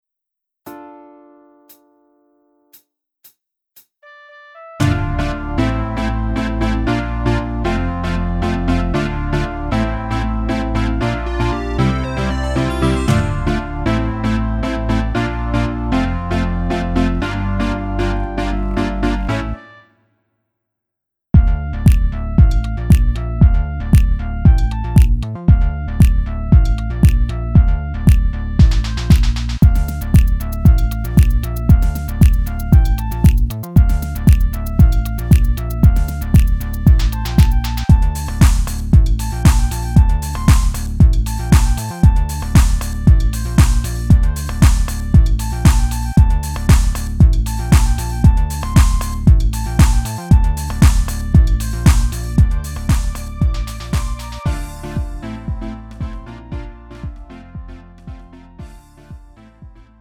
-멜로디MR 가수
장르 가요